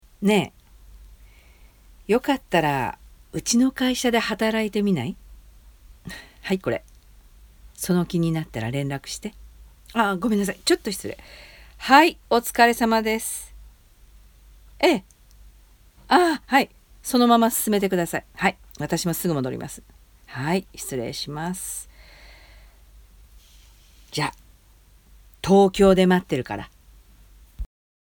キャリアウーマン
ボイスサンプル